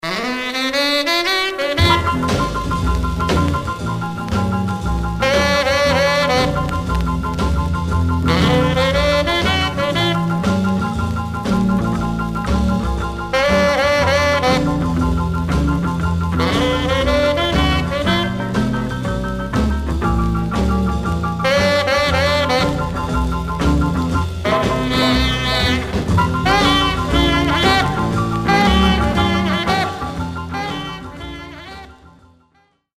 Some surface noise/wear
Mono
R&B Instrumental Condition